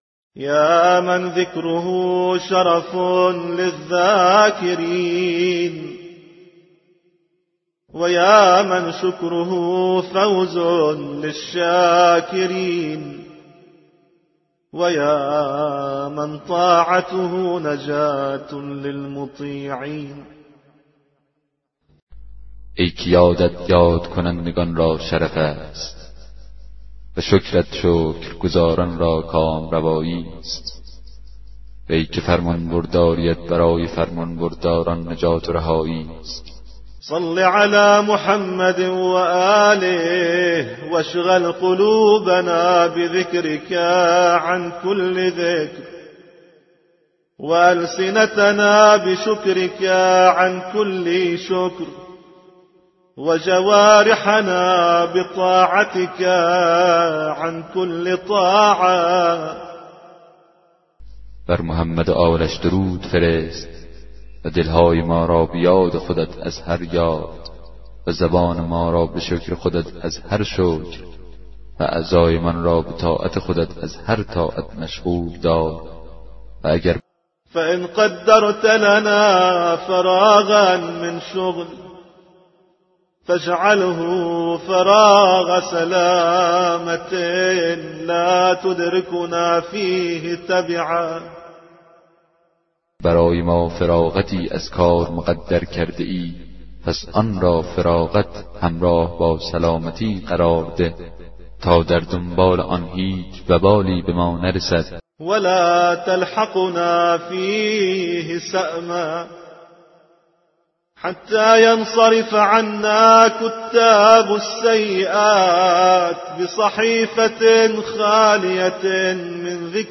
کتاب صوتی دعای 11 صحیفه سجادیه